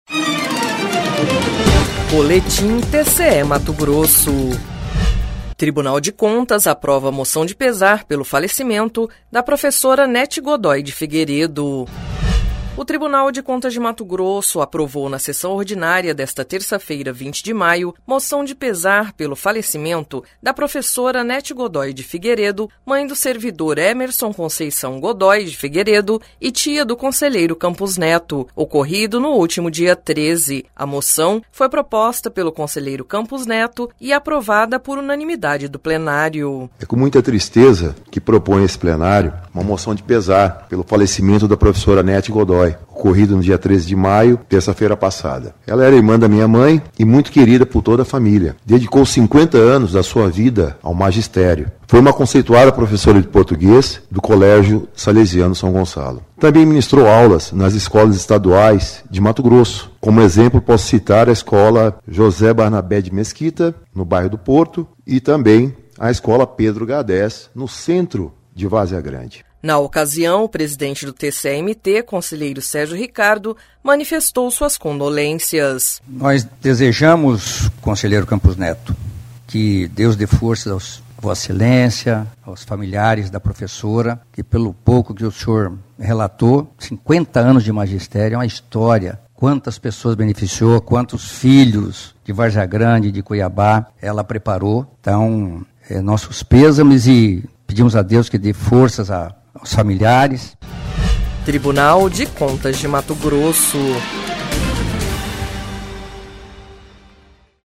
Proposta pelo conselheiro Campos Neto, a homenagem foi prestada na sessão ordinária desta terça-feira (20).
Sonora: Campos Neto – conselheiro do TCE-MT
Sonora: Sérgio Ricardo - presidente do TCE-M